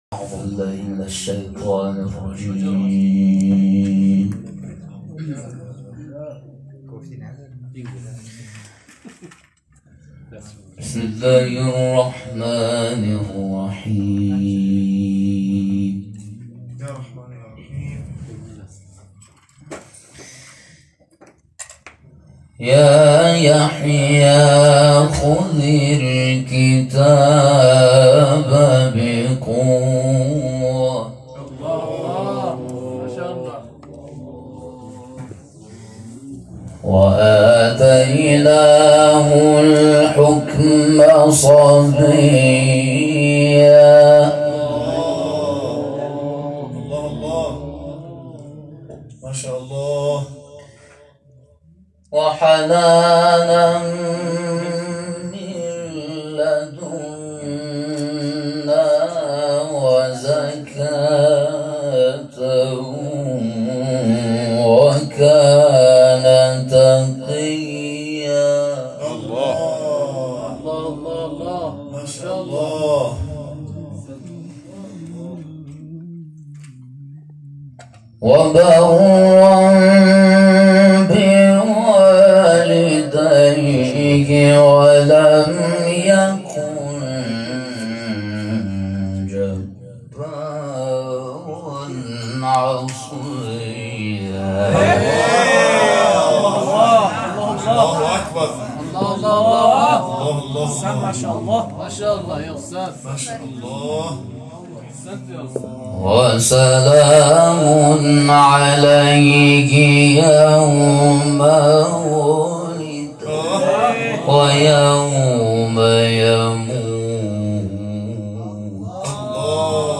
تلاوت فنی و معنامحور